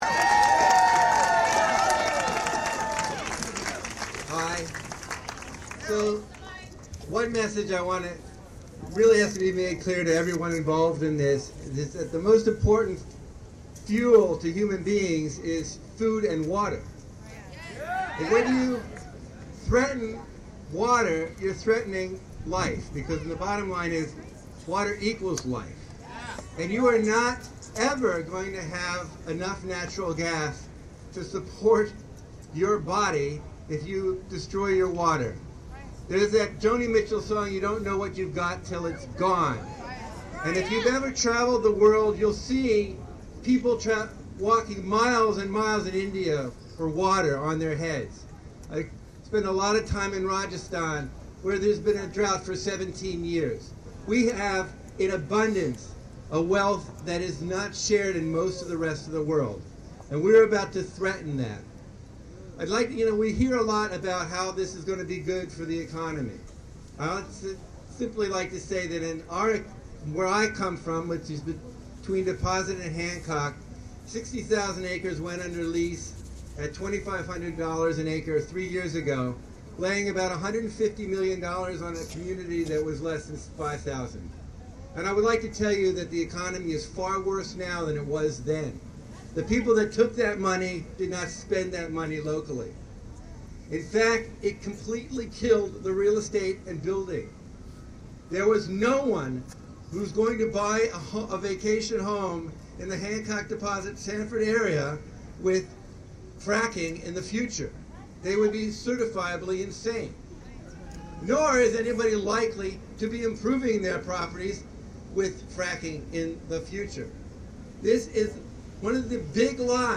at Albany Earth Day rally May 2.